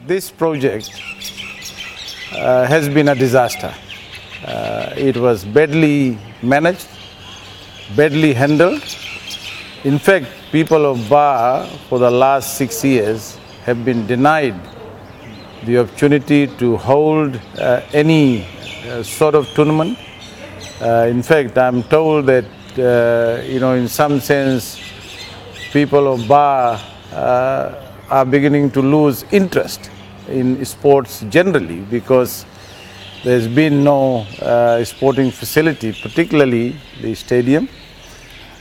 Finance Minister Professor Biman Prasad during his visit to the Govind Park Stadium in Ba.